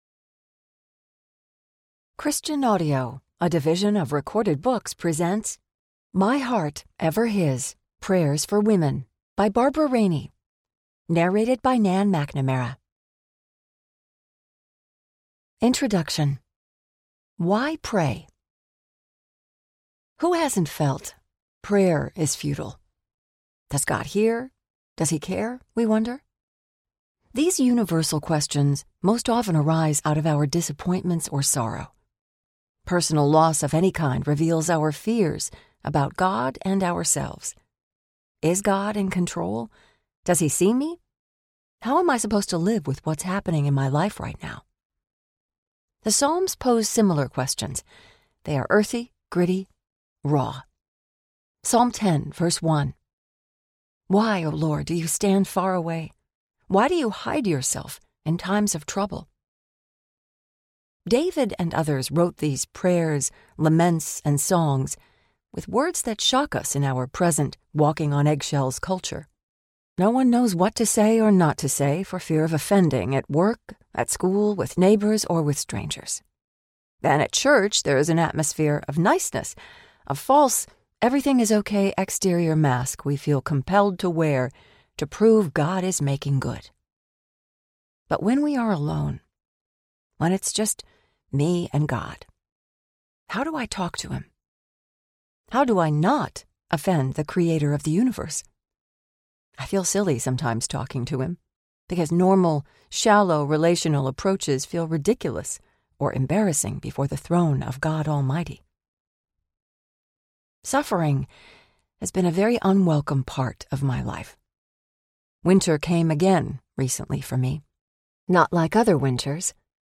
My Heart, Ever His Audiobook
Narrator
2.7 Hrs. – Unabridged